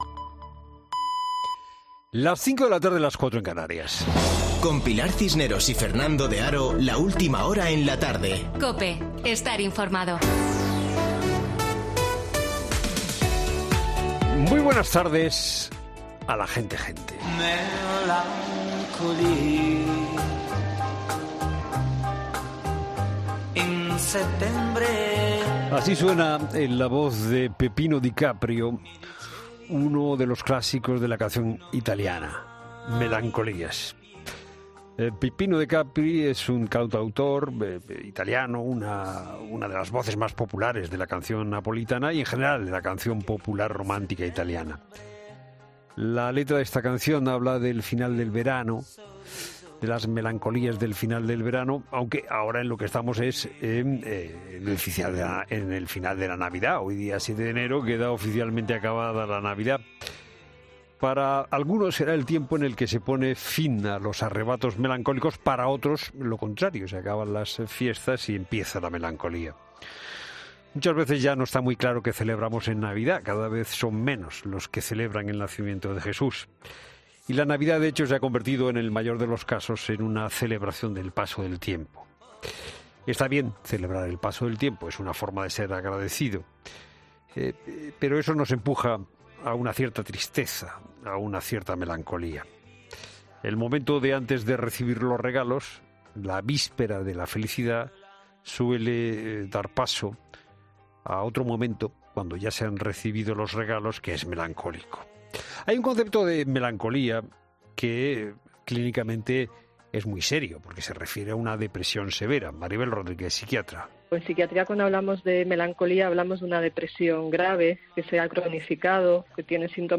Boletín de noticias COPE del 7 de enero de 2022 a las 17:00 horas